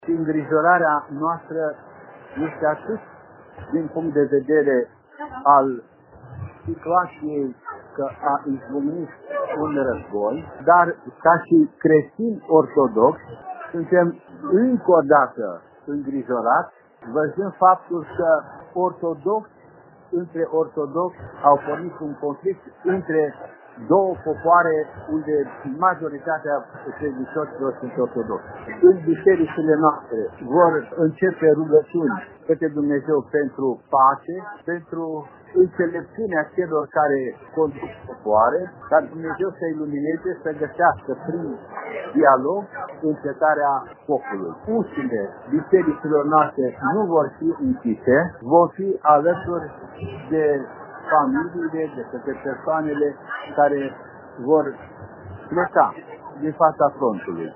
Înalt Prea Sfinția Sa, Ioan, mitropolitul Banatului, îi cheamă pe credincioși să se roage pentru pacea din Ucraina.